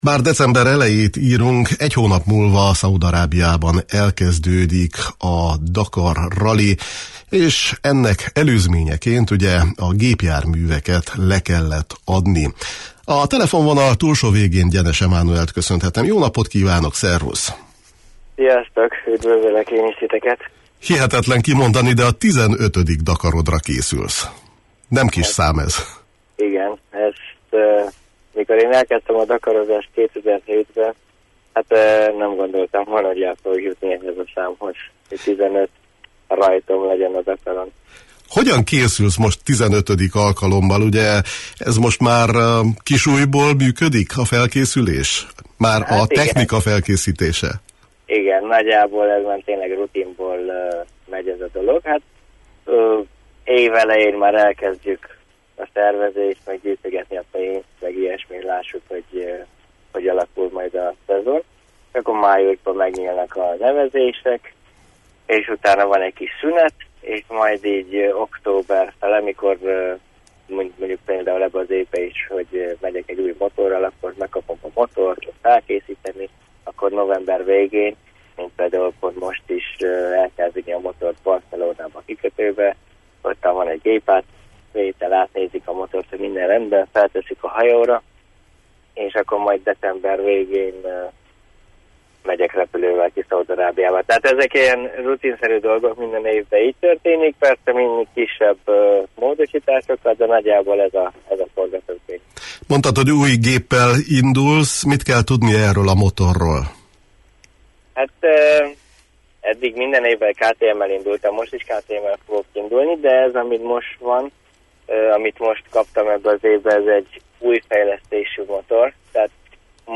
A szatmárnémeti motoros arról is beszélt a Kispad című sportműsorunkban, hogy mennyivel könnyebb, vagy akár nehezebb egy ilyen versenyen való elinduláshoz összegyűjteni a támogatást.